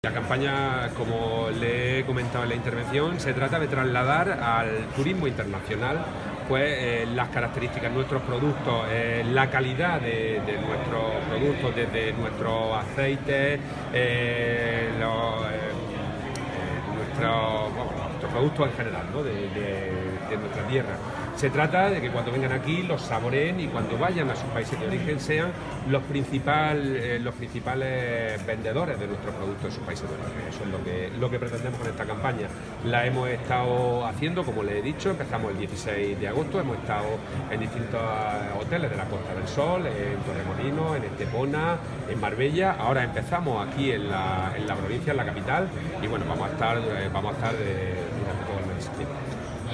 Declaraciones de Sánchez Haro_Campaña Tasty Andalucía